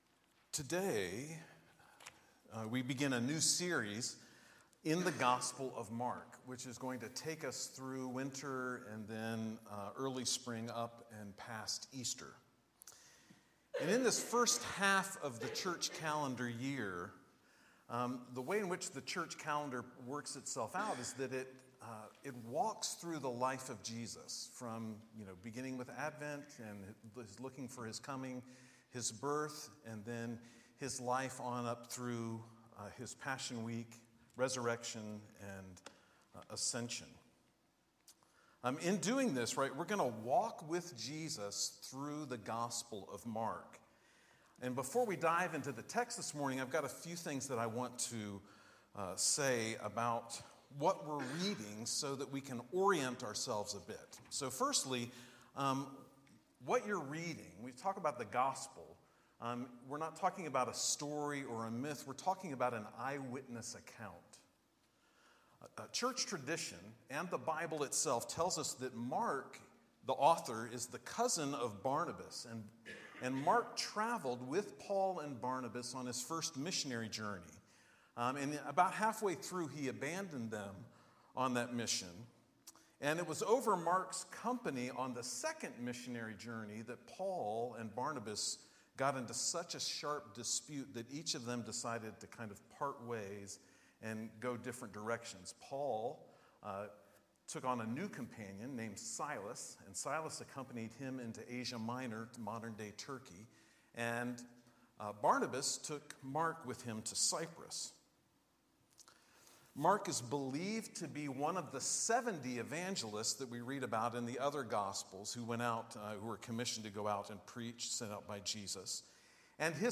Sermon and Teaching